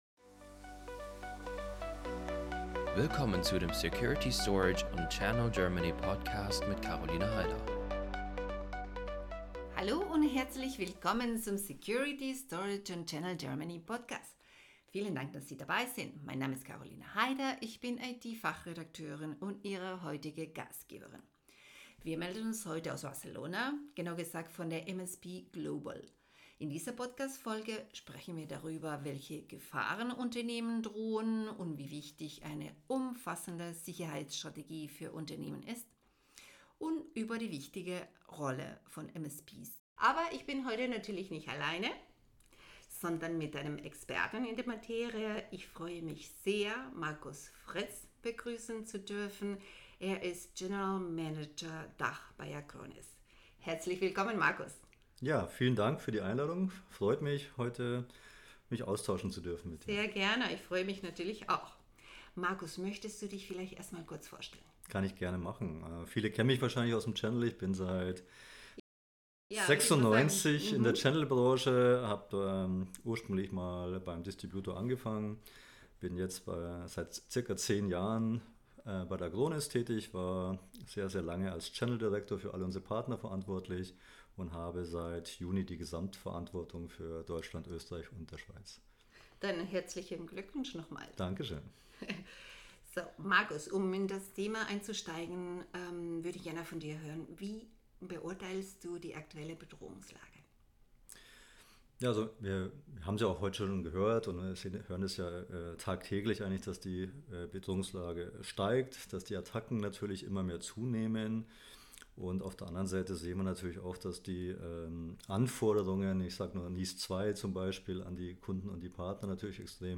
Por qué las empresas necesitan una ciberseguridad integral – Entrevista